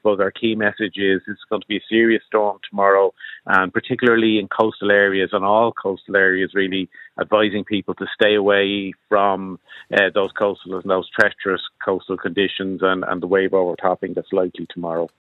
Director of the National Directorate for Fire and Emergency Management, Keith Leonard, says conditions can change quickly: